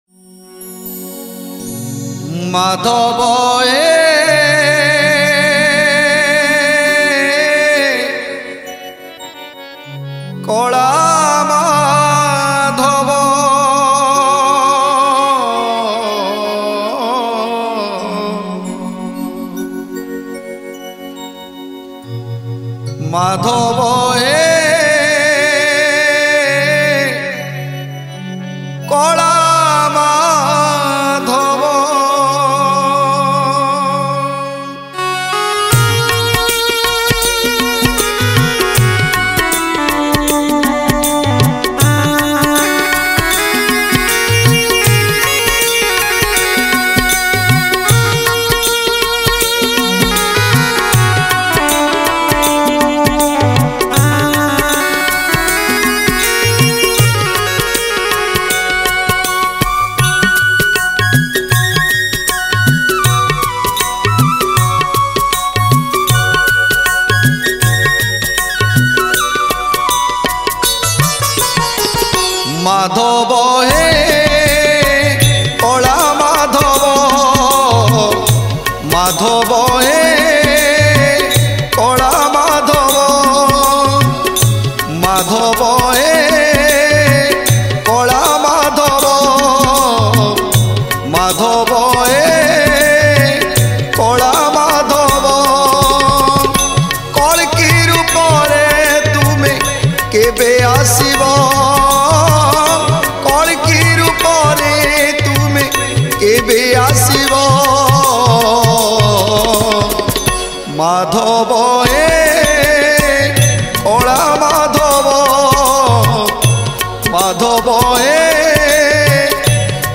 Sri Sri Jagannath Stuti
Soulful Heart touching singer